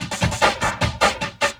45 LOOP 08-R.wav